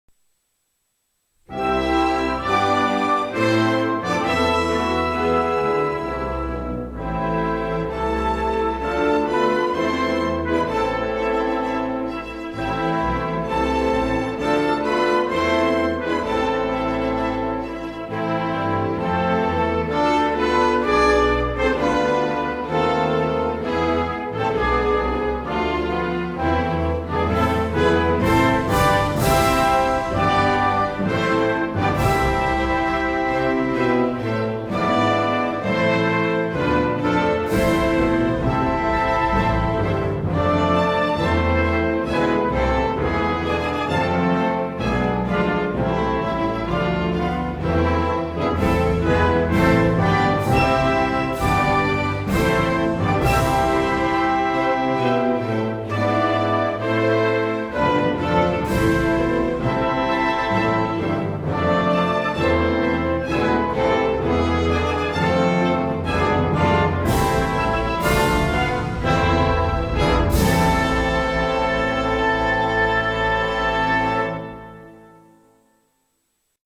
торжественную мелодию